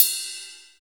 LITERIDEBEL.wav